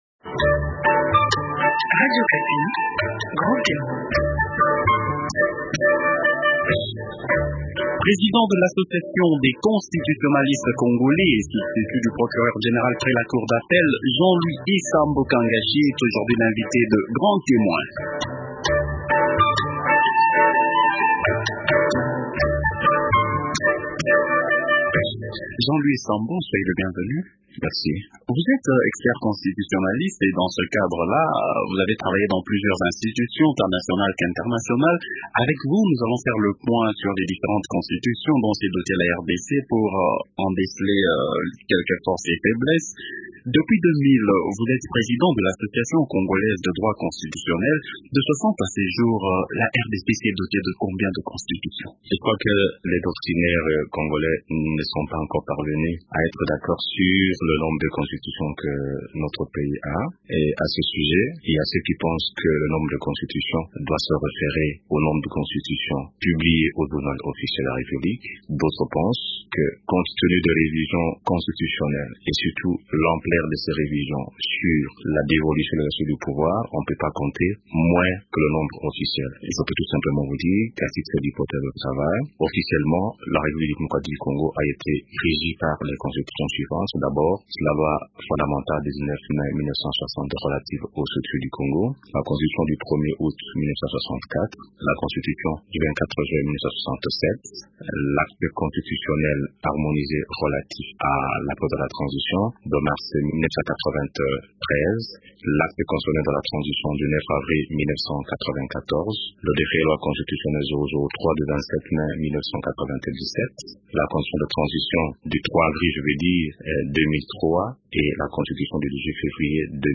analyste politique